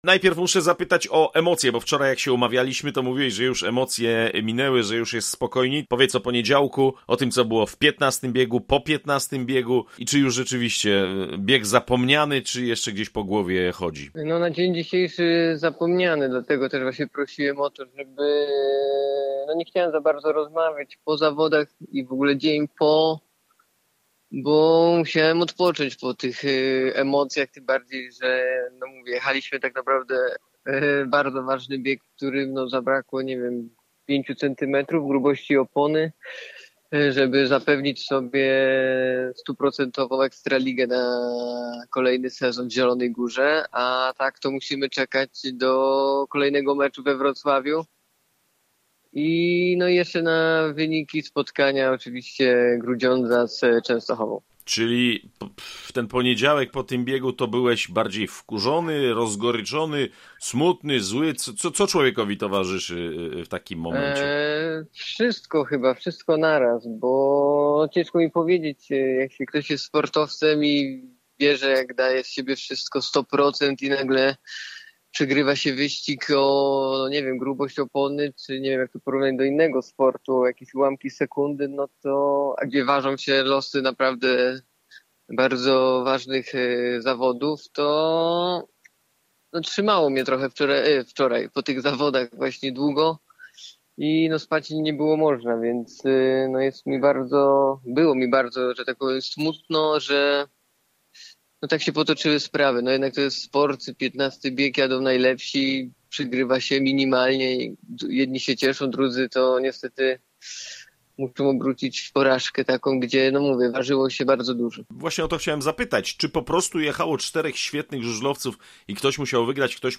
W rozmowie z Radiem Zielona Góra Dudek nie ukrywa, że sytuacja zespołu Piotra Żyty wisi na włosku i prawdopodobnie o ligowym bycie zdecyduje mecz GKM Grudziądz – Włókniarz Częstochowa.